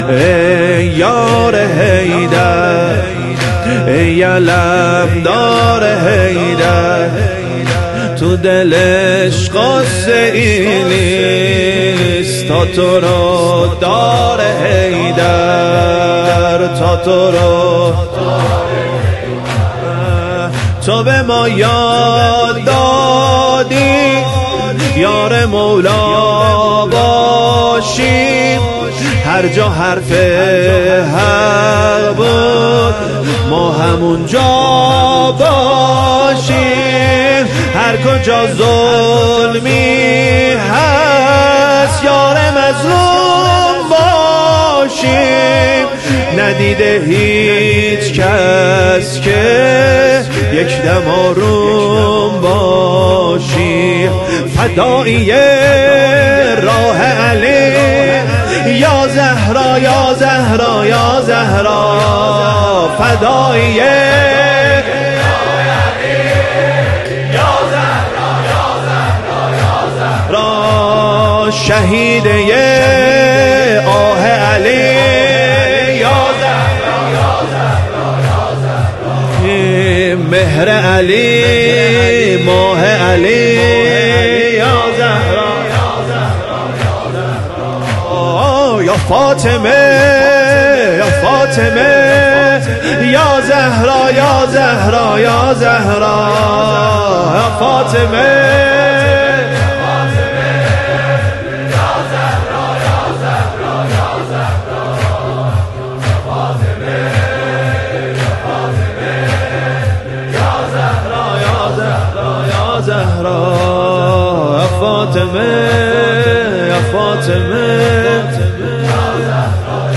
زمینه | ای یار حیدر
سینه زنی زمینه
ایام فاطمیه دوم - شب سوم